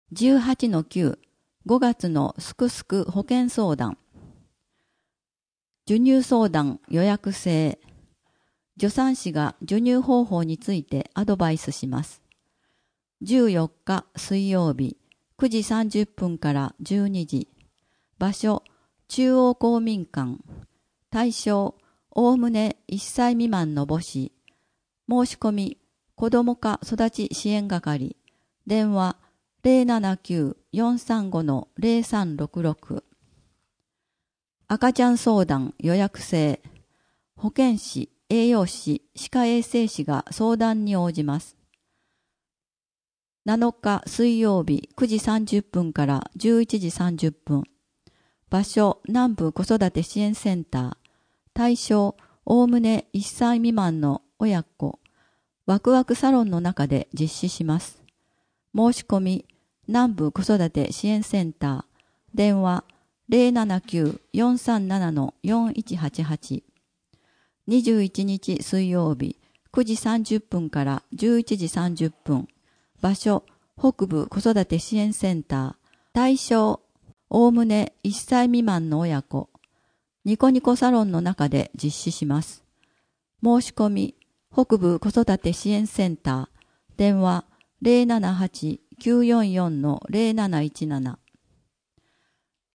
声の「広報はりま」はボランティアグループ「のぎく」のご協力により作成されています。